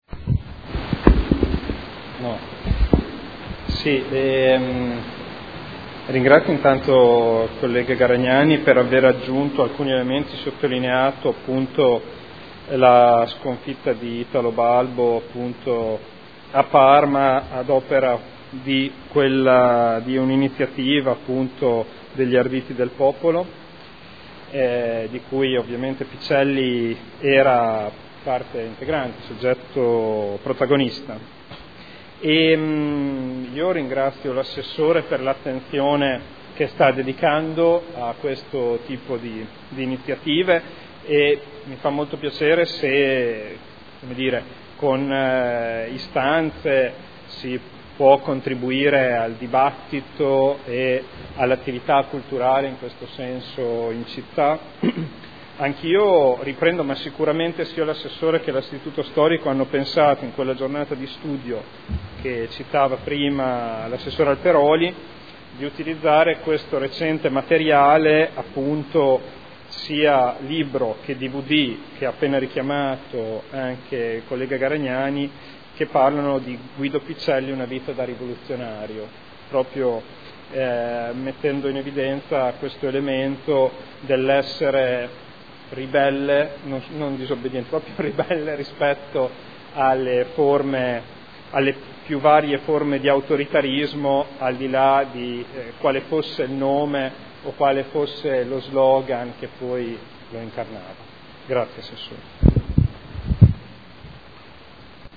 Seduta del 31/10/2013. Conclude dibattito su interrogazione del consigliere Ricci (SEL) avente per oggetto: “Guido Picelli”